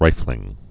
(rīflĭng)